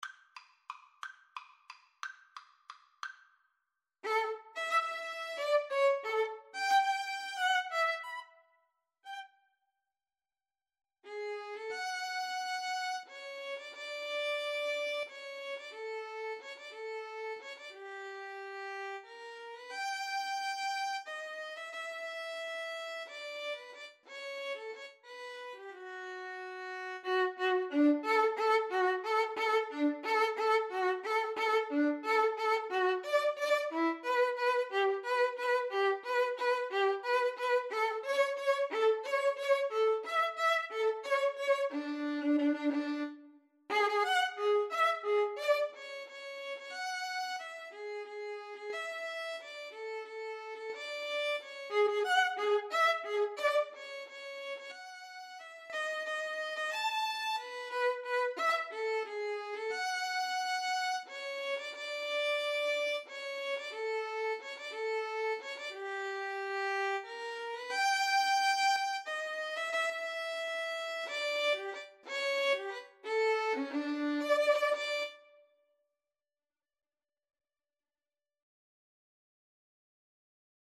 3/4 (View more 3/4 Music)
~ = 180 Tempo di Valse
D major (Sounding Pitch) (View more D major Music for Violin Duet )